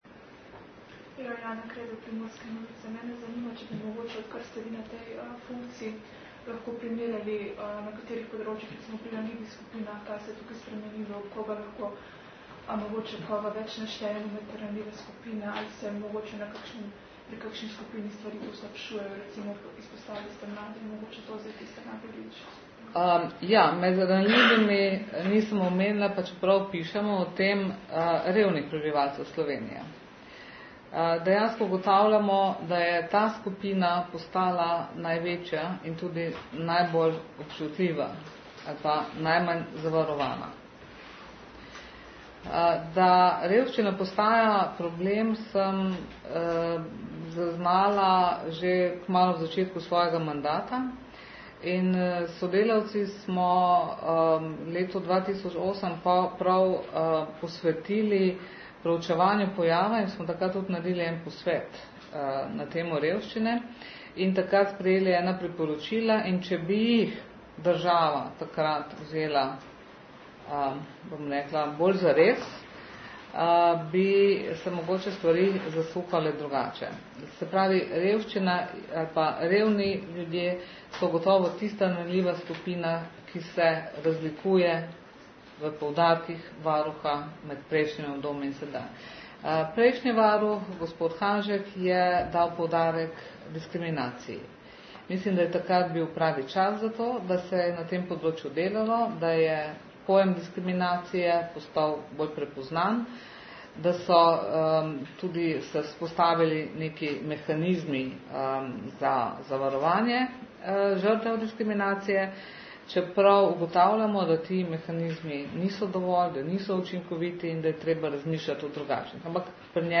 Zvočni posnetki novinarske konference:
Varuhinja je podrobnosti iz že šestnajstega letnega poročila predstavila na novinarski konferenci.
NOVKONF_LP10_-_vprasanja_novinarjev_.mp3